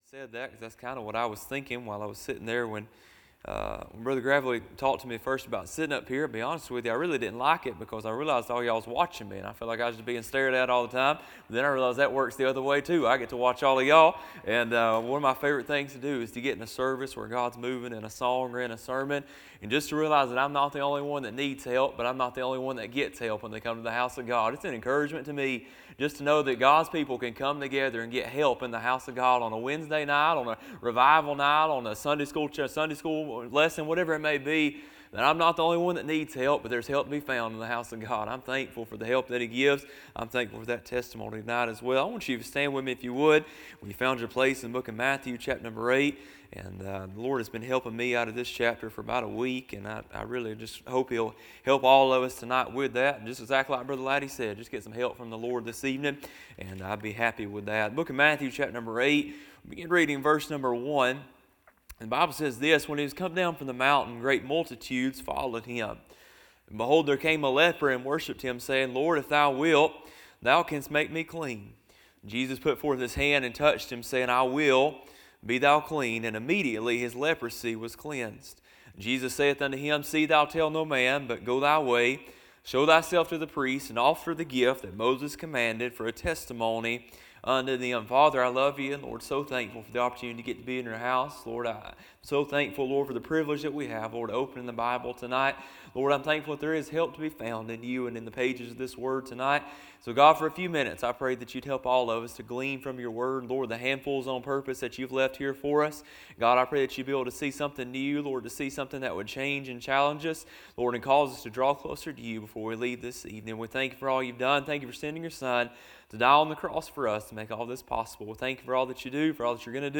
A sermon preached Wednesday Evening, on February 25, 2026.